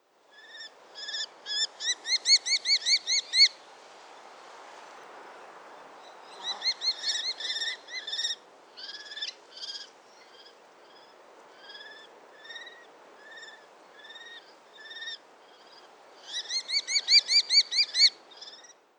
Its shrill call recorded locally can be heard by clicking on the audio bar below.
nankeen-kestrel.mp3